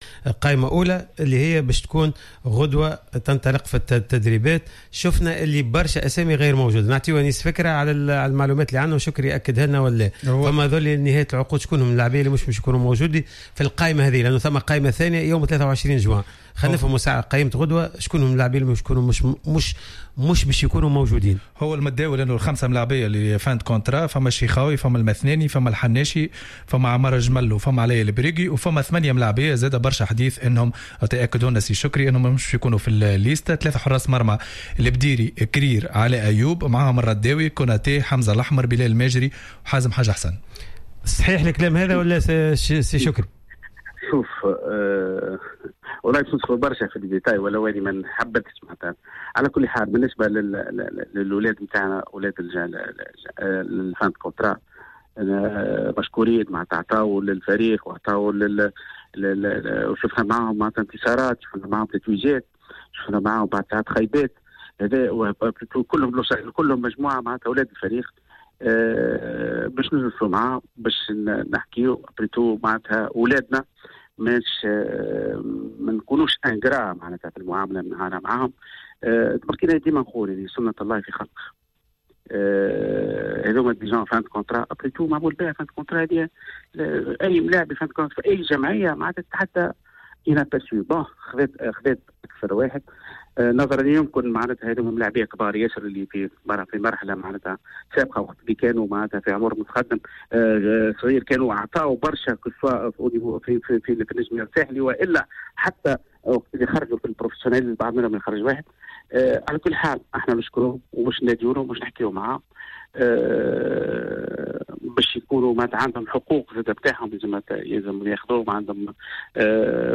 مداخلة في حصة "راديو سبور" أن المرحلة القادمة ستعرف إنهاء عقود عدد من اللاعبين.